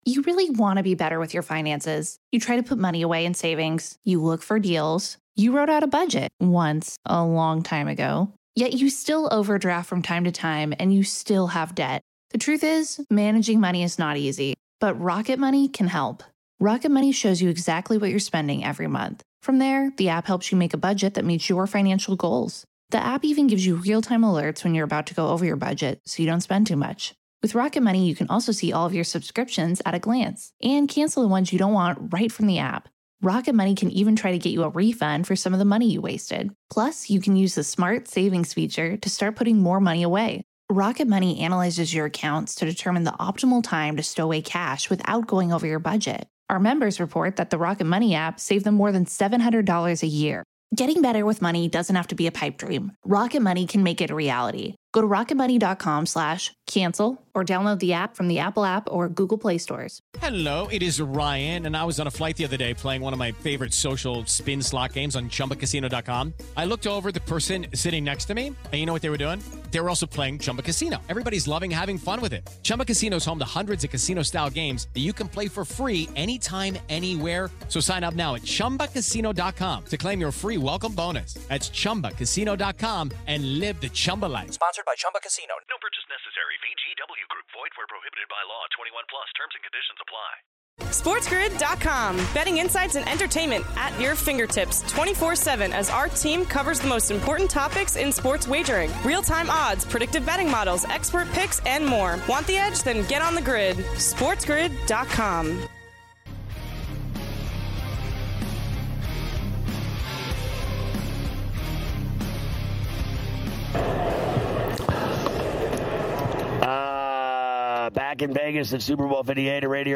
2/7 Hour 3: Ferrall Live From Radio Row in Las Vegas!
On this episode, Ferrall welcomes the biggest guests all week live from Radio Row in Las Vegas as he prepares for Super Bowl LVIII!
Legendary sports shock jock Scott Ferrall takes the gaming world by storm with his “in your face” style, previewing the evening slate of games going over lines, totals and props, keeping you out of harms way and on the right side of the line.